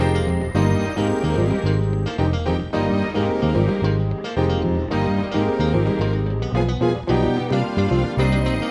13 Backing PT3.wav